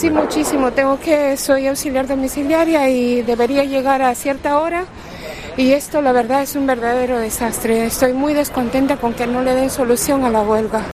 Afectados por la huelga del servicio público se quejan de las afecciones en el transporte
El micrófono de COPE País Vasco ha estasdo esta mañana en la céntrica estación de San Mamés en Bilbao y en la estación intermodal donde diferentes usuarios han contado su hastío por los retrasos que ha generado la jornada de paro.